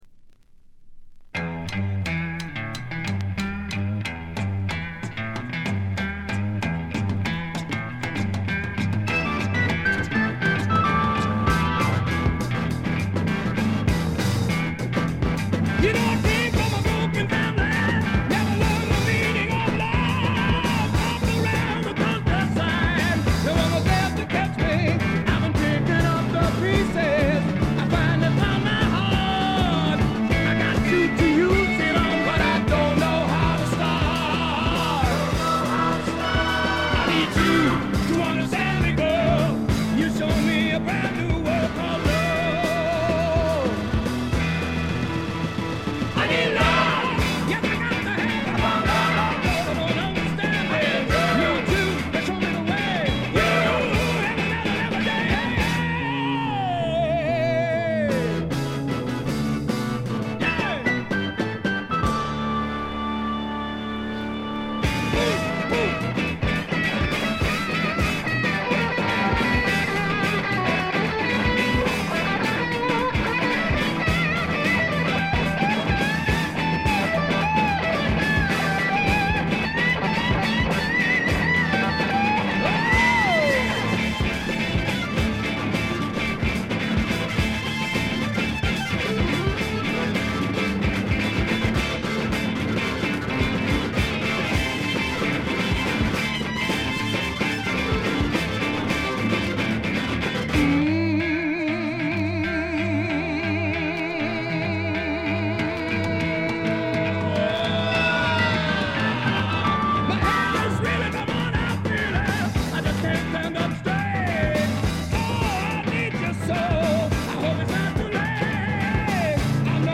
69年という最良の時代の最良のブルース・ロックを聴かせます。
試聴曲は現品からの取り込み音源です。